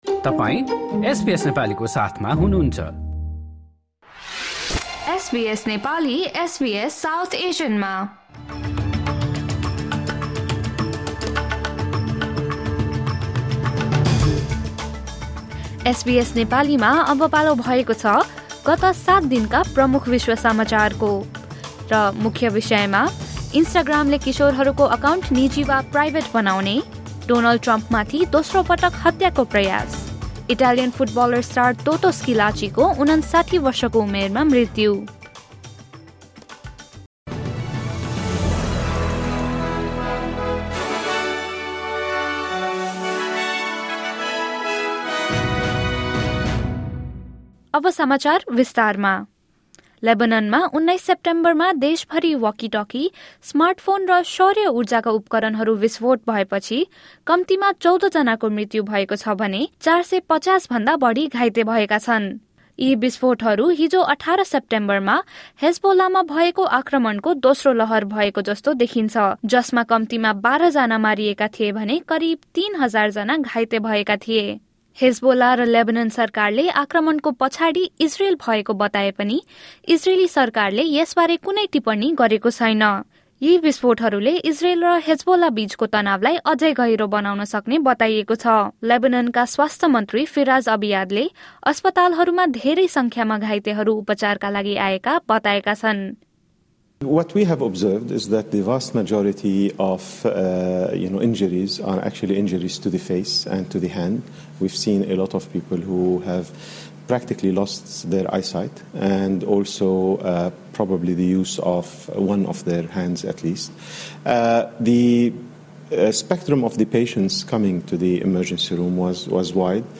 एसबीएस नेपाली विश्व समाचार: गत सात दिनका प्रमुख घटना